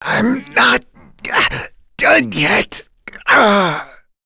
Dimael-Vox_Dead.wav